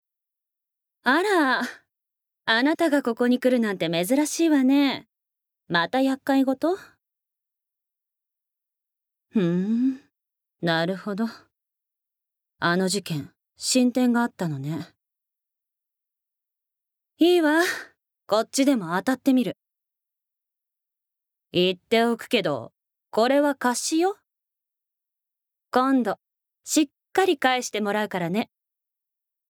Voice Sample
ボイスサンプル
セリフ５